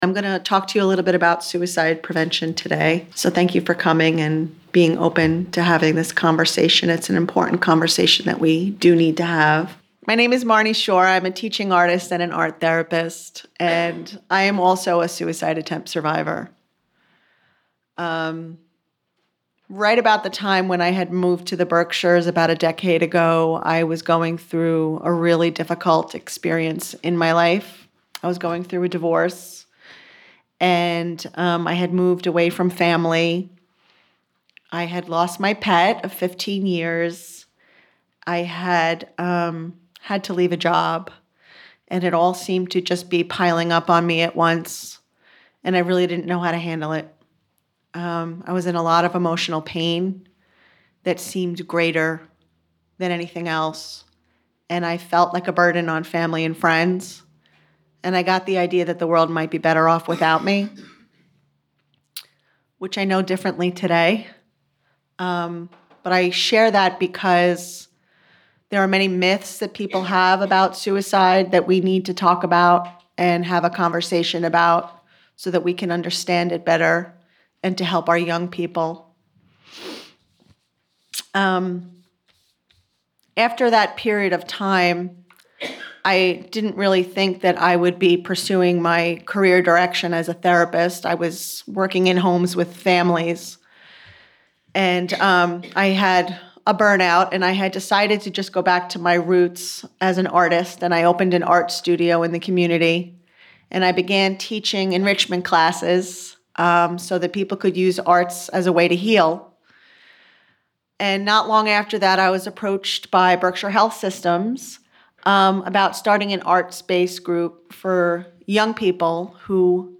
Public Talk on Youth Suicide Prevention
Unitarian Universalist Church, Pittsfield MA